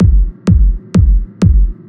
• Techno Reverb Clean Kick.wav
Techno_Reverb_Clean_Kick-1_VYO.wav